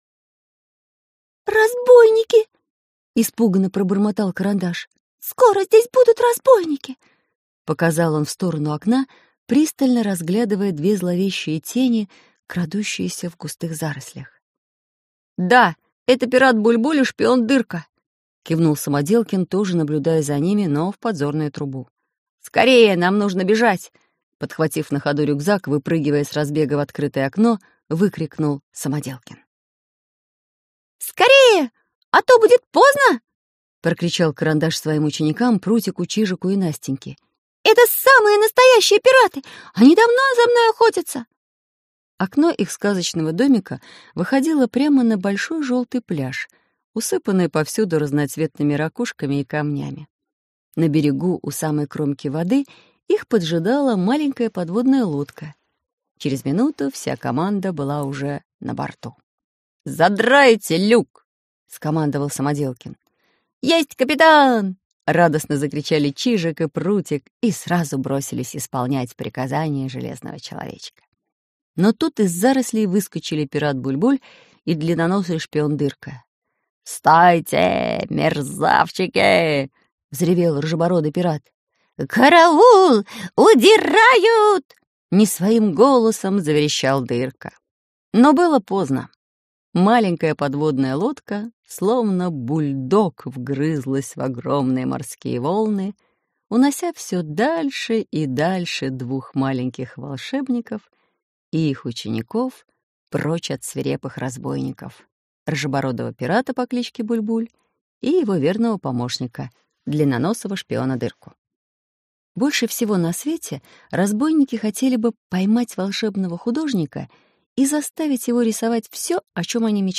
Аудиокнига Карандаш и Самоделкин на необитаемом острове | Библиотека аудиокниг